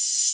Open Hat [ stargazing ].wav